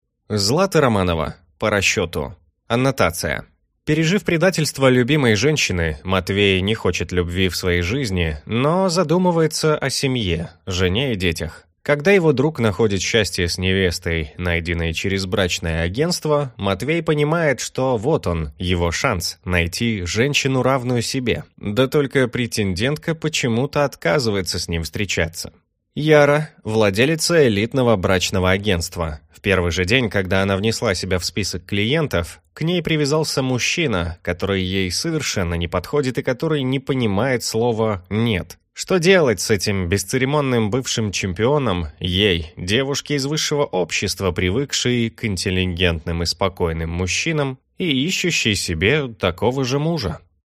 Аудиокнига По расчету | Библиотека аудиокниг
Прослушать и бесплатно скачать фрагмент аудиокниги